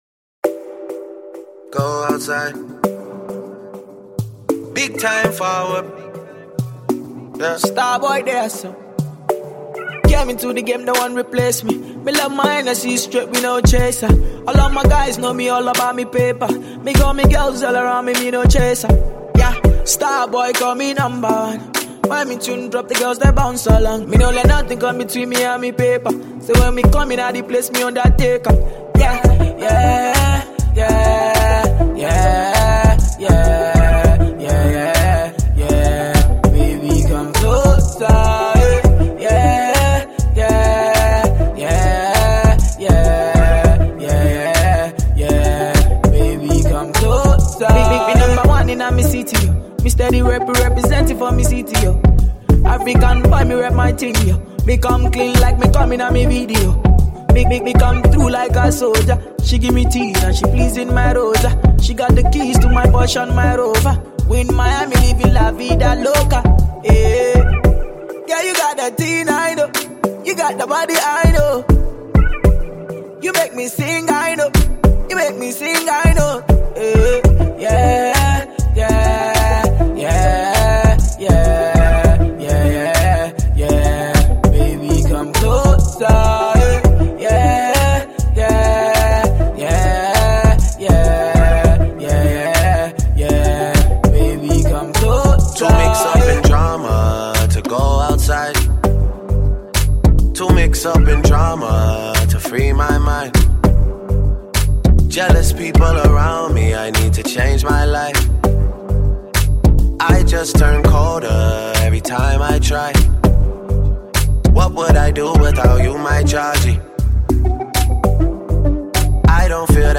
dancehall beat